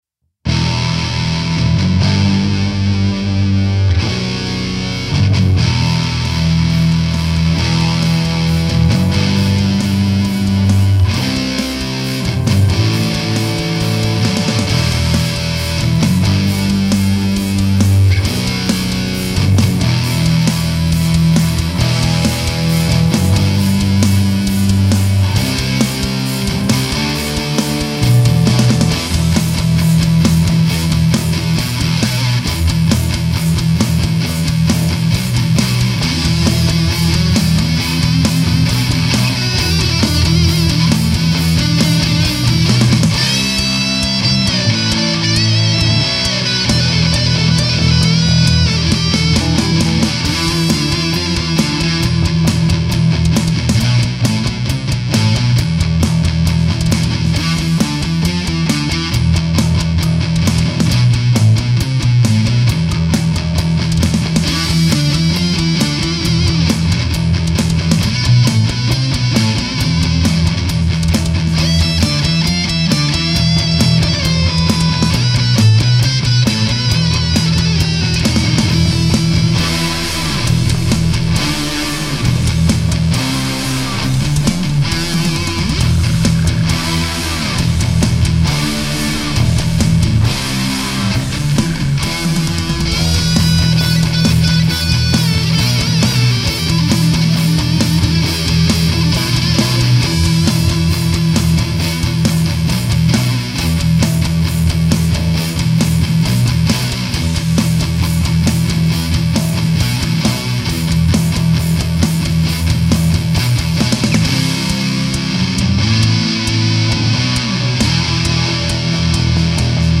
Need feedback/critique on mixing